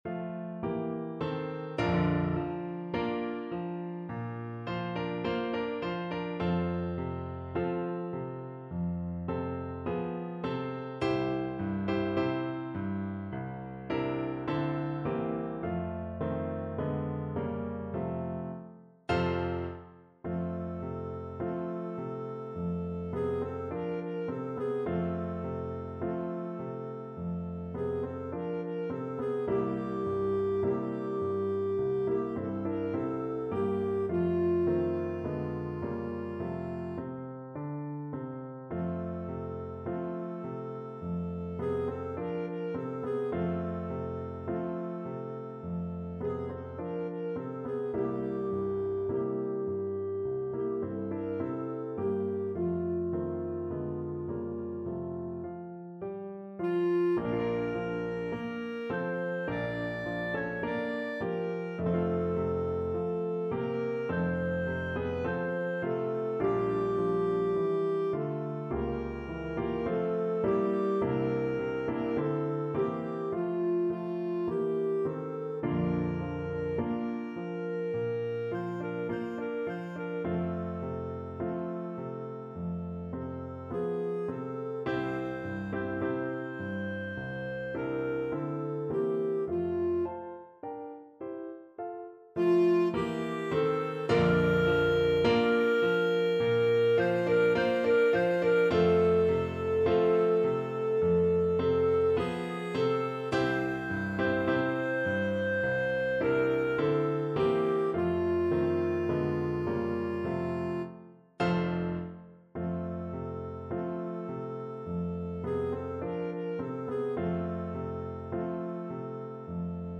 2/4 (View more 2/4 Music)
Andantino =c.52 (View more music marked Andantino)
Classical (View more Classical Clarinet Music)